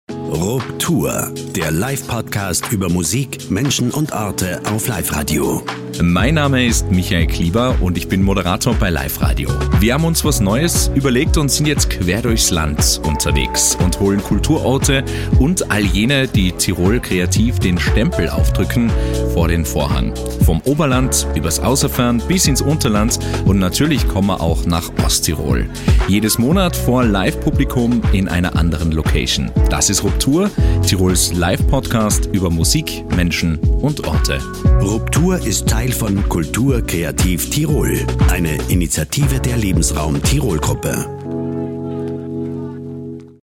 TRAILER RUPTUR – Tirols Live-Podcast über Musik, Menschen und Orte.